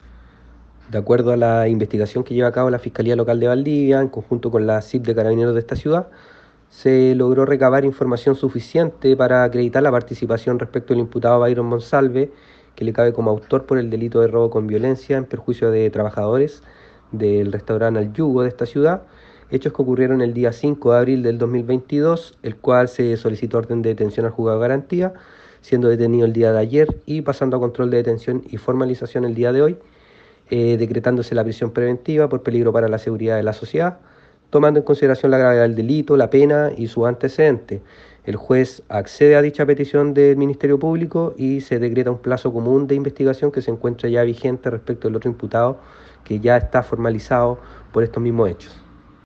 fiscal Alejandro López